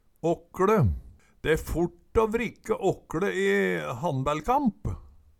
åkkLe - Numedalsmål (en-US)